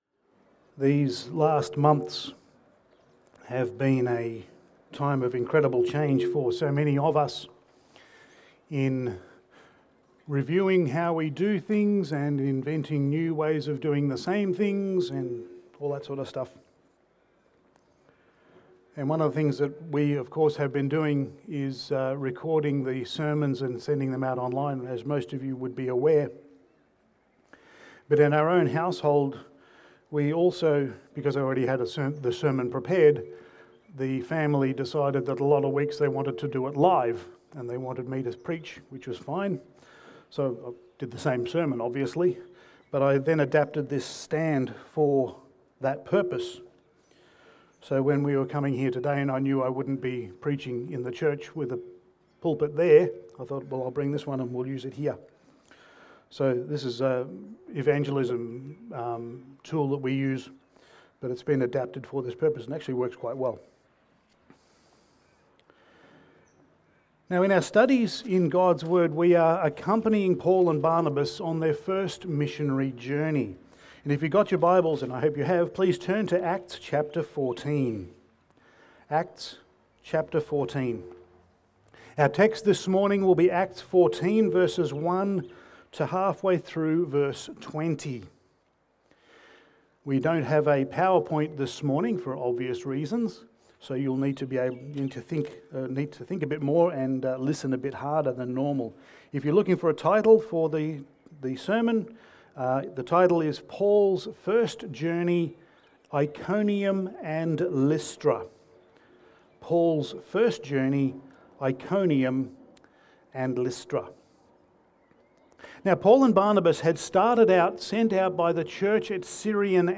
Passage: Acts 14:1-20 Service Type: Sunday Morning